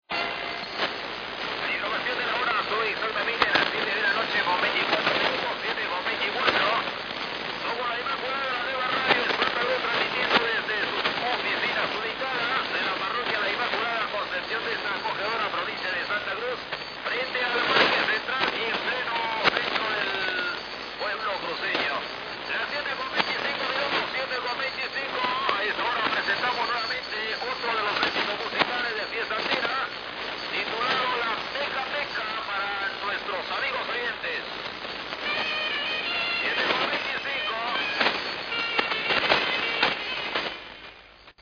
Peru on SW